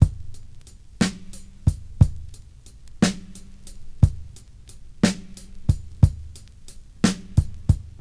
• 60 Bpm Breakbeat C Key.wav
Free drum beat - kick tuned to the C note. Loudest frequency: 834Hz
60-bpm-breakbeat-c-key-cgF.wav